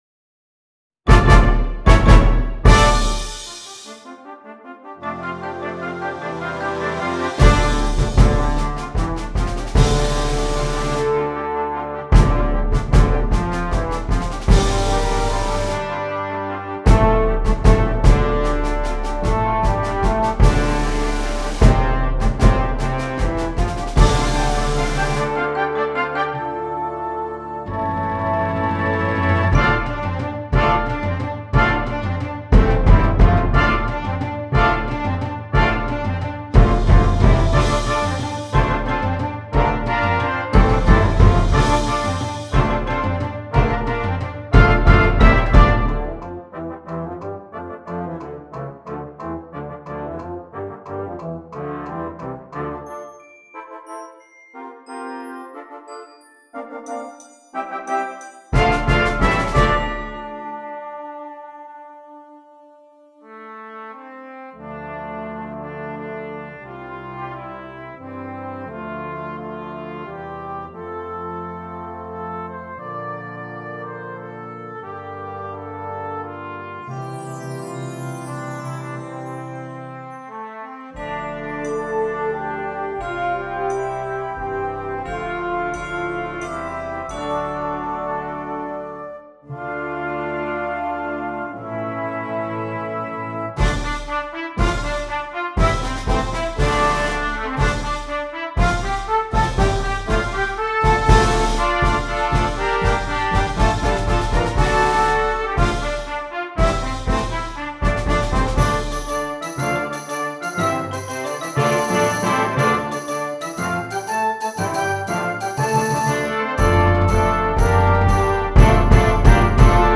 Voicing: 13 Brass and Percussion